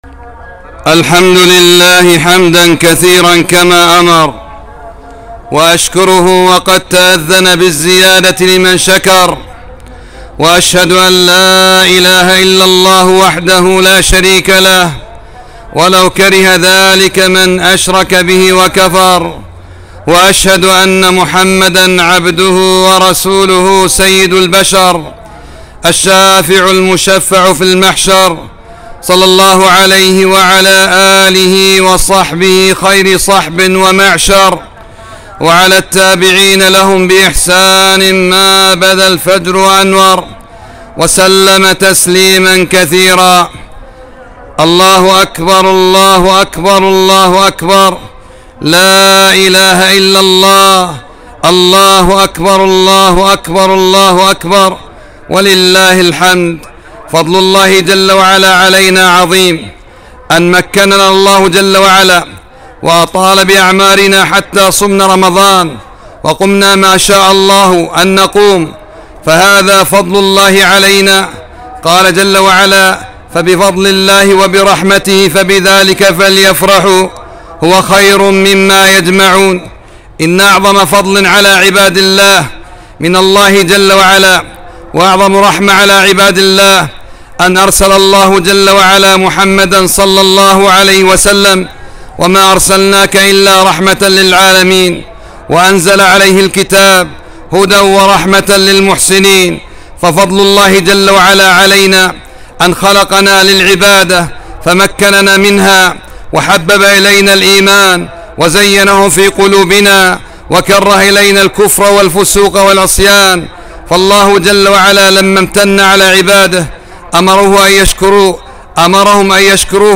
خطبة عيد الفطر ١٤٤٣